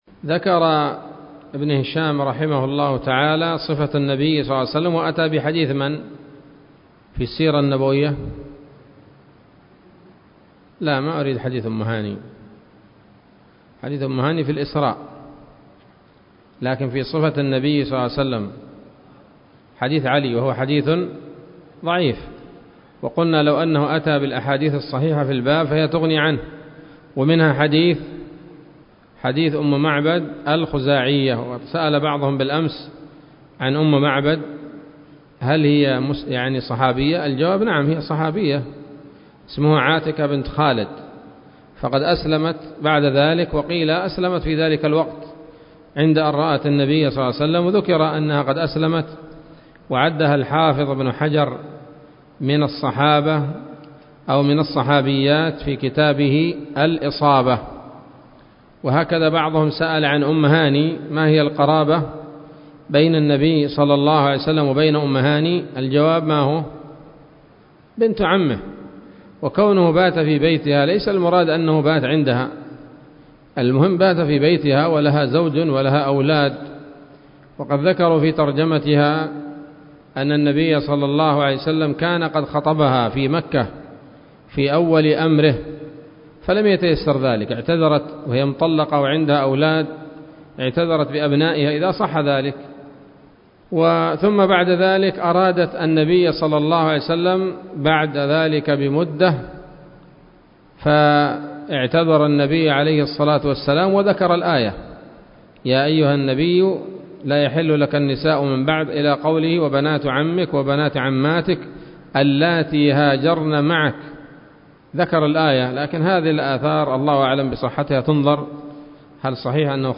الدرس الخمسون من التعليق على كتاب السيرة النبوية لابن هشام